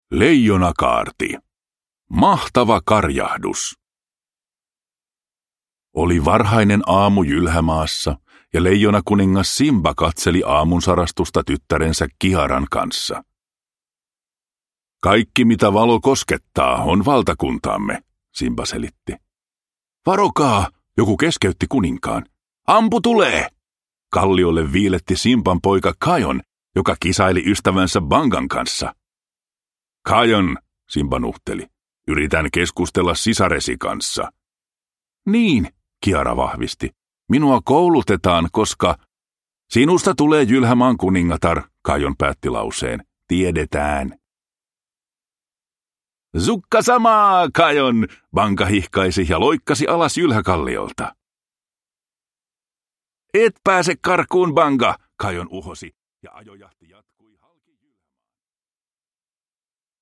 Leijonakaarti. Mahtava karjahdus – Ljudbok – Laddas ner